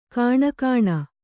♪ kāṇakāṇa